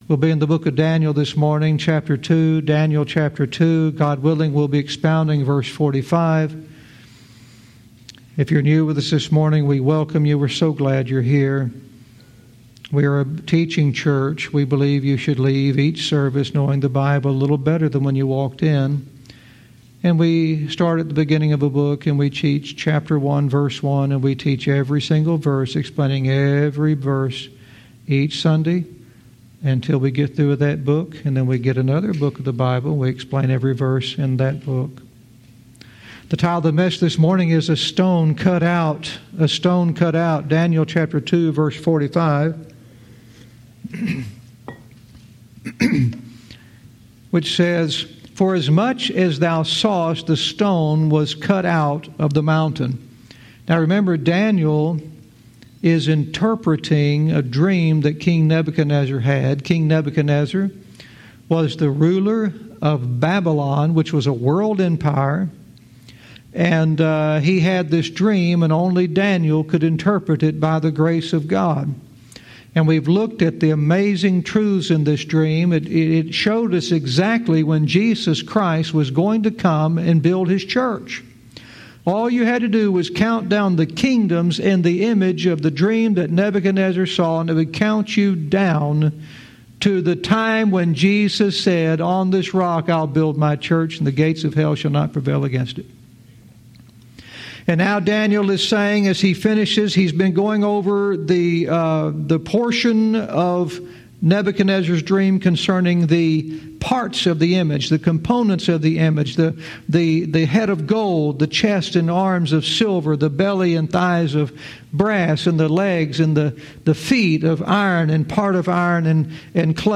Verse by verse teaching - Daniel 2:45 "A Stone Cut Out"